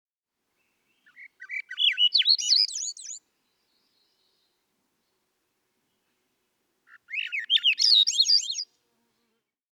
This subtly marked skulker has a rising, flutelike song that reverberates throughout forests and woodlands across the northern portion of North America.